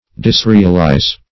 Search Result for " disrealize" : The Collaborative International Dictionary of English v.0.48: disrealize \dis*re"al*ize\, v. t. To divest of reality; to make uncertain.